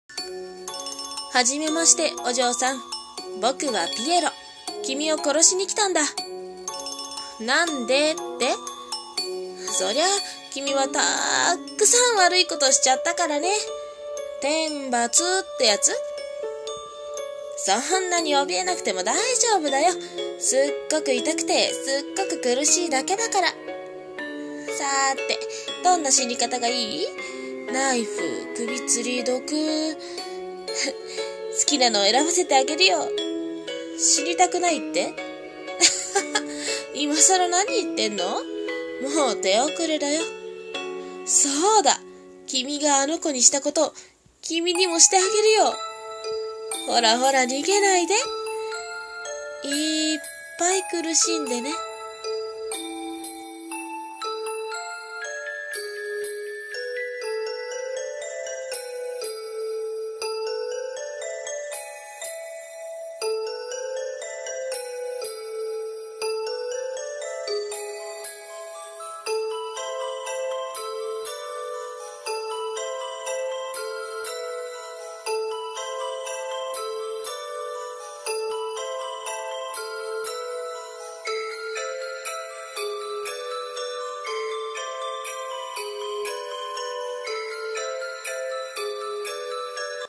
【声劇】道化師